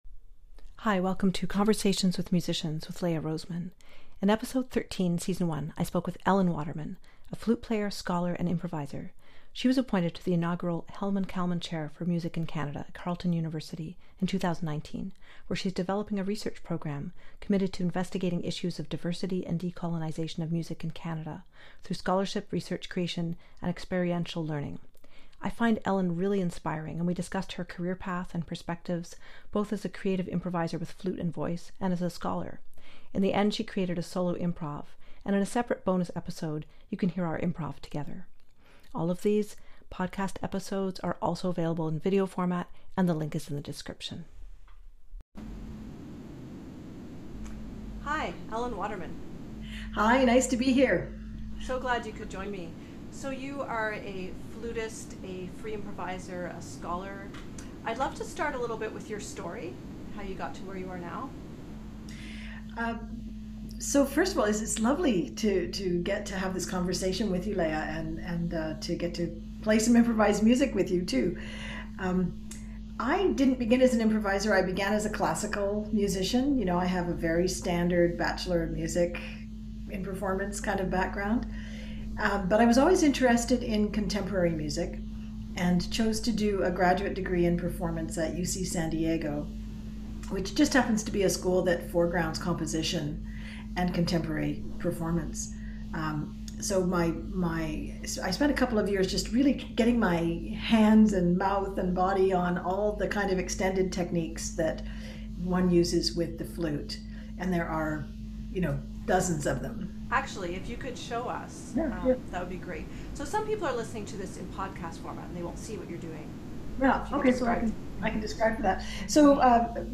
At the end treats us to a solo improv.
This was recorded July 21, 2021 via Zoom